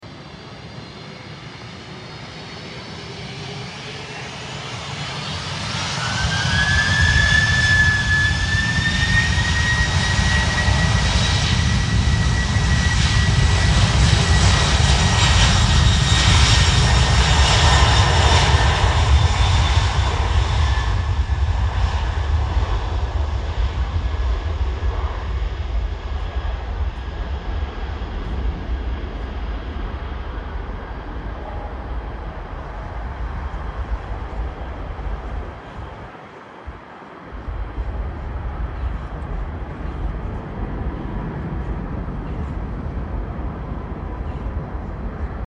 BOEING 747-446F N537CA National Airlines